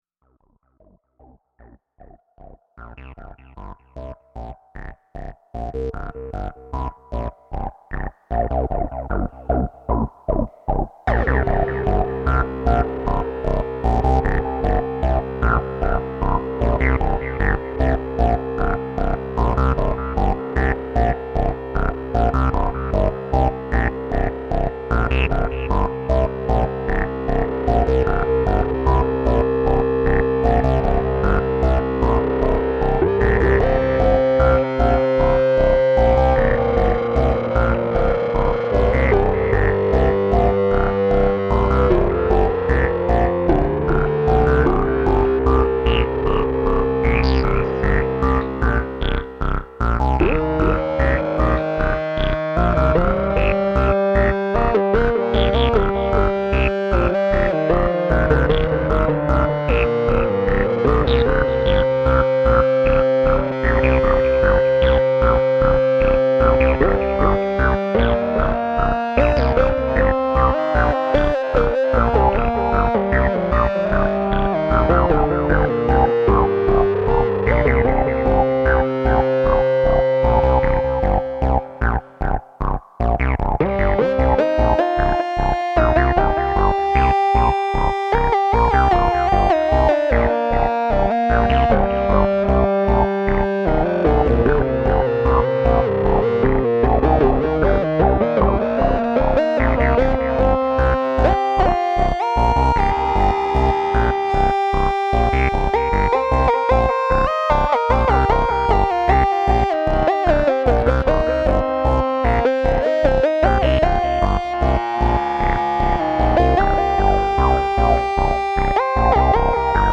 The first row of the MAQ is playing the riff through the Little Phatty. I then split that signal - you can hear the "clean" track (going through my old MF-104) in the left channel. In the right channel I have it going through the MF-101, with the frequency being controlled via the third row of the MAQ by CV (and played through Space Echo). I think I was modulating the cutoff frequency of the filter in the LP too, with the lowest LFO setting.
The lead is messing around with the "overdriver" preset.